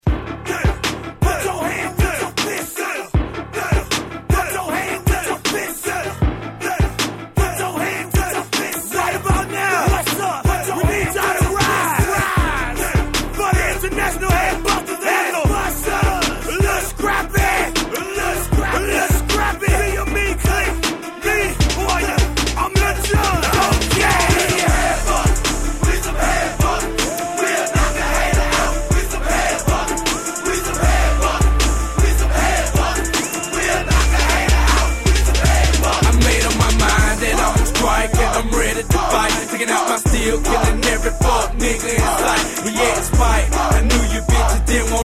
03' Smash Hit Southern Hip Hop !!